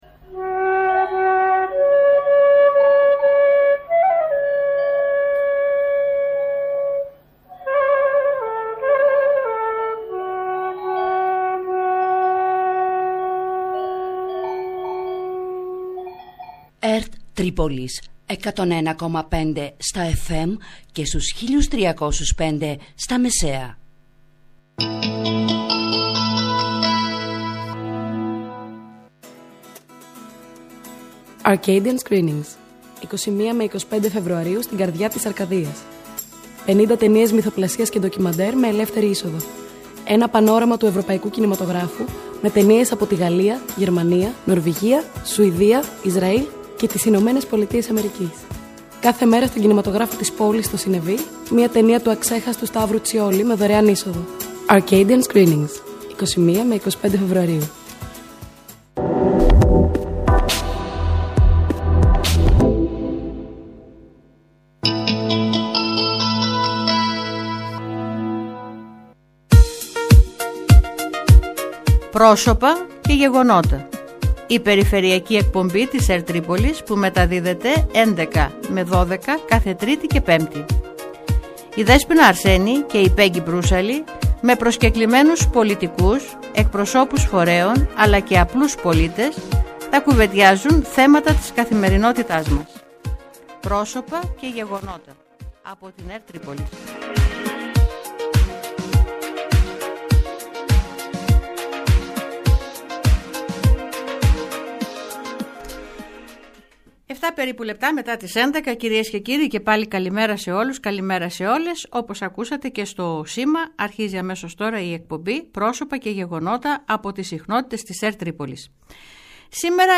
Στις προτεραιότητες της Περιφέρειας Πελοποννήσου και στον σχεδιασμό που έχει καταρτιστεί για τα επόμενα χρόνια αναφέρθηκε ο Αντιπεριφερειάρχης Αρκαδίας Χρήστος Λαμπρόπουλος, που φιλοξενήθηκε σήμερα Τρίτη 25 Φεβρουαρίου 2020, στο ραδιοθάλαμο της ΕΡΤ Τρίπολης.